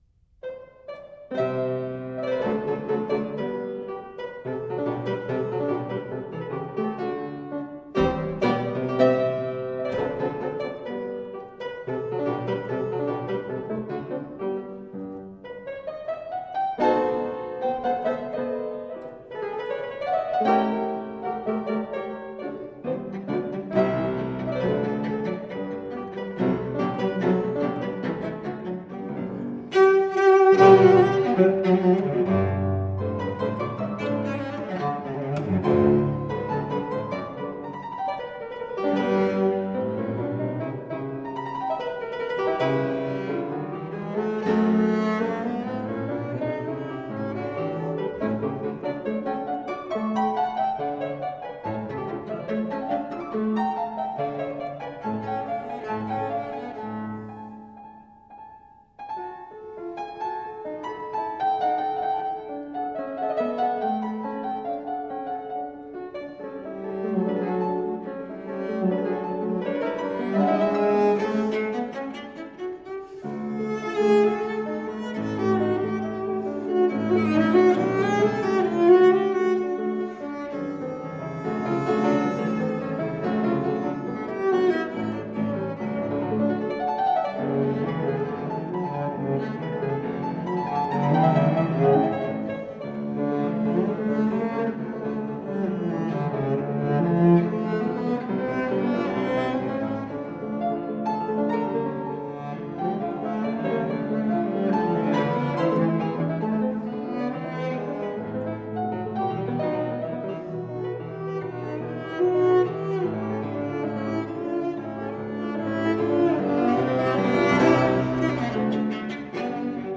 Cello, Classical Piano